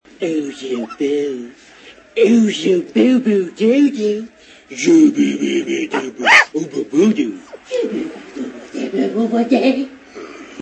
Ace talks to a dog
dogtalk.wav